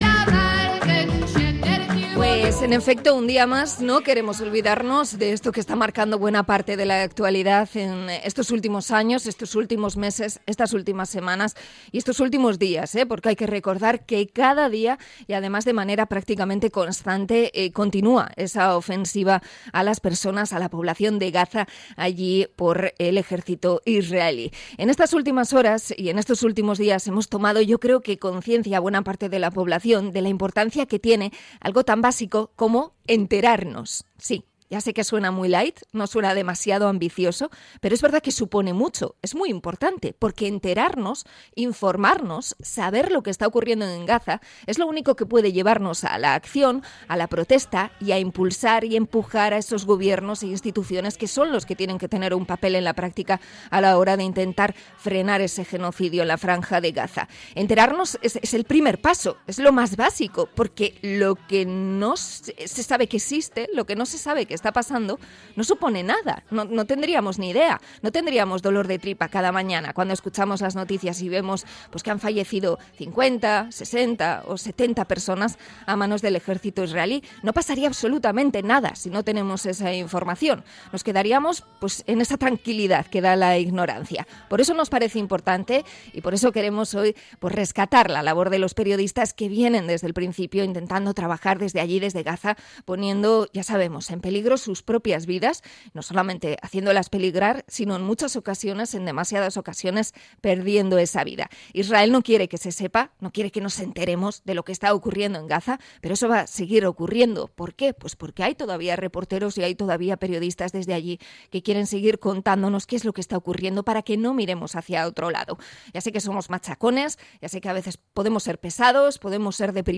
Entrevista a MSF por los casi 250 periodistas asesinados en Gaza a manos de Israel